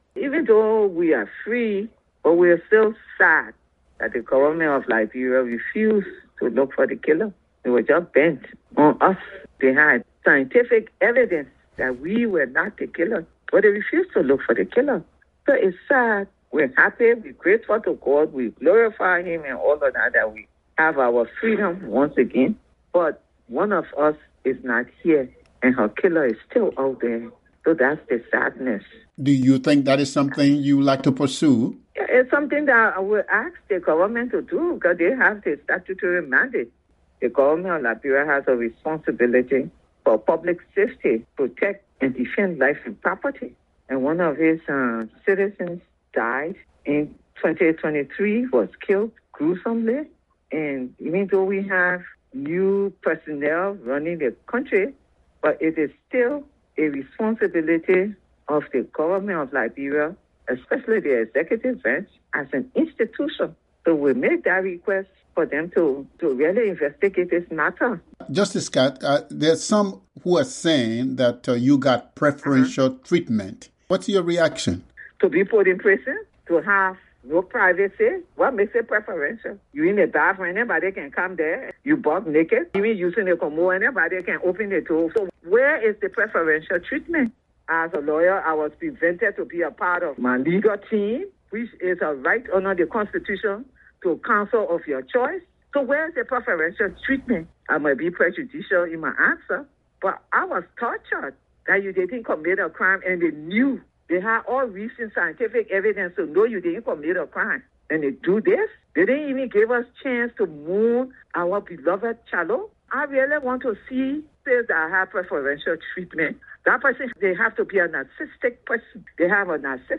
In an exclusive interview with VOA, Justice Musu Scott says she will use her experience in jail to fight for prison and judicial reforms, including a code of conduct, with penalties, for police and prosecutors.